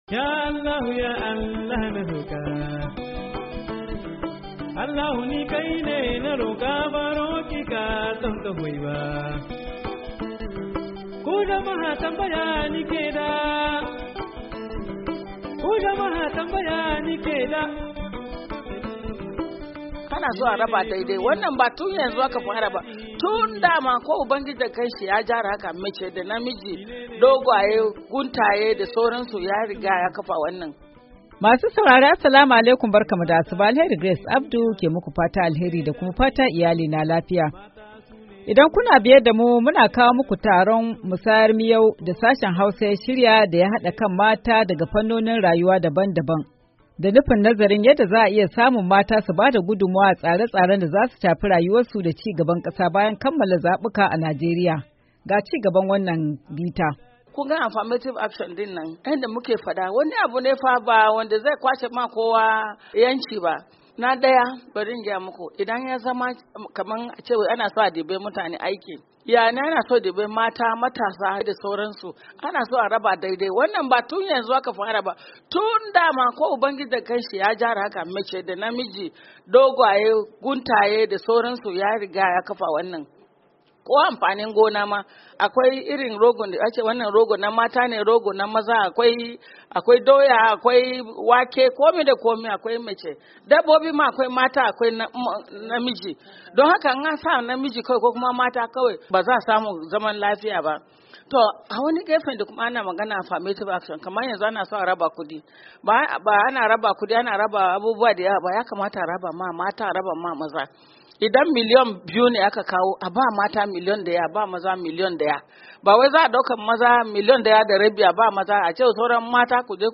Idan kuna biye damu muna kawo maku taron musayar miyau da Sashen Hausa ya shirya da ya hada kan mata daga fannonin rayuwa dabam dabam da nufin nazarin yadda za a iya samu mata su bada gudummuwa a tsare tsaren da zasu shafi rayuwarsu da ci gaban kasa bayan kamala zabuka a Najeriya.
Tattaunawa kan siyasar mata-PT3-10:"